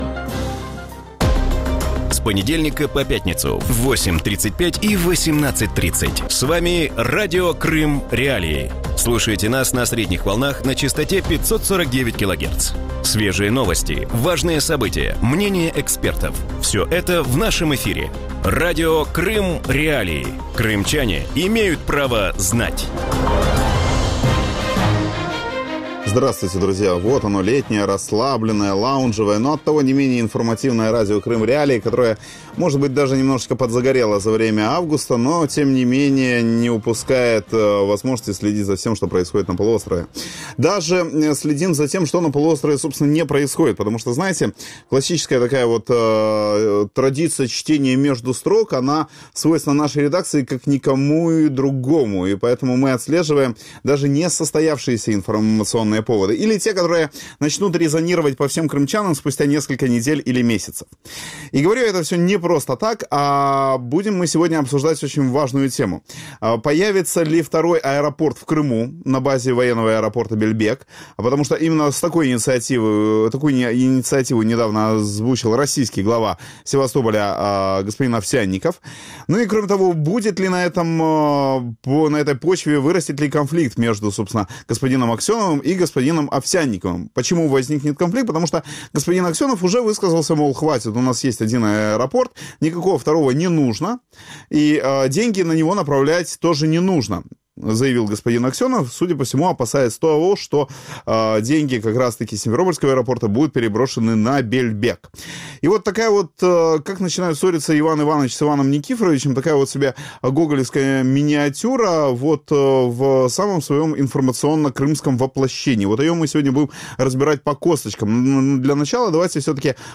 У вечірньому ефірі Радіо Крим.Реалії обговорюють ініціативу кримської влади додати сектор цивільної авіації до військового аеропорту «Бельбек» у Севастополі. Чи потрібен в Криму додатковий аеродром, чи реально його побудувати в умовах санкцій і хто виступає проти цієї ідеї?